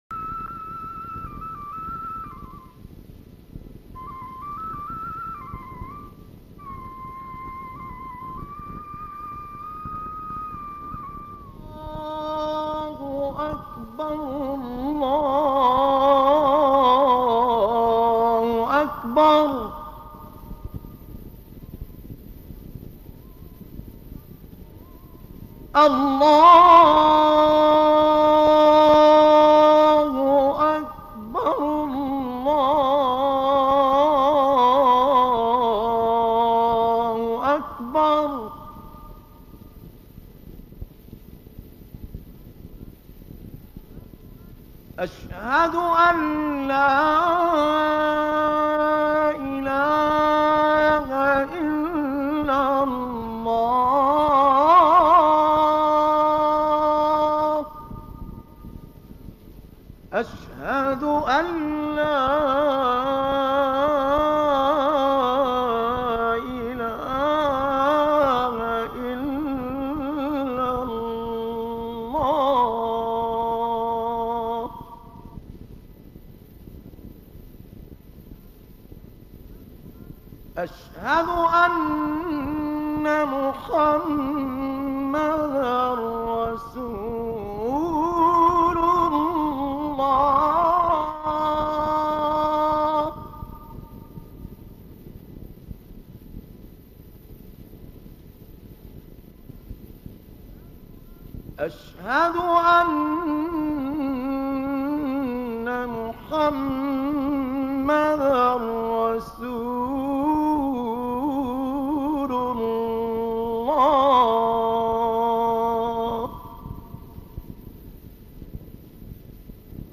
گروه فعالیت‌های قرآنی: گلبانگ اذان، با صدای دلنشین 9 قاری بین‌المللی را می‌شنوید.
اذان مرحوم نصرالدین طوبار